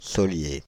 Soliers (French pronunciation: [sɔlje]
Fr-Paris--Soliers.ogg.mp3